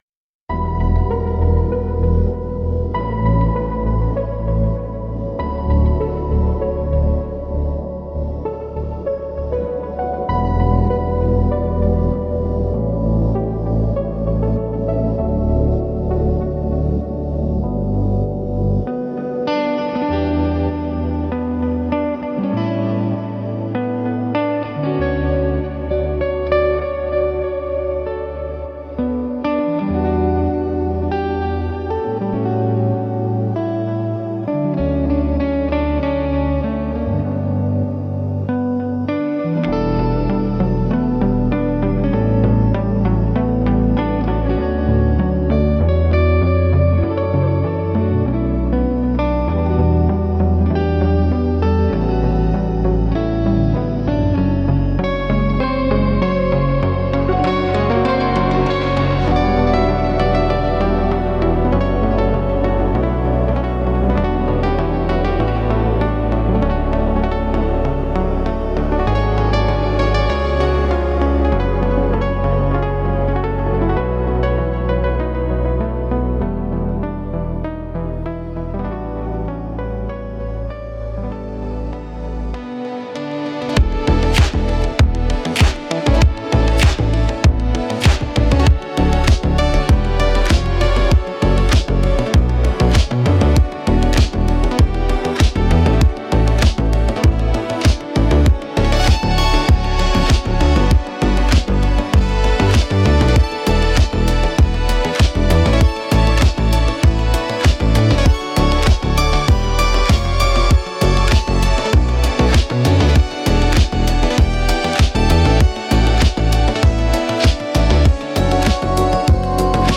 Synthwave AI covers of songs that shouldn't exist this way.
AI COVER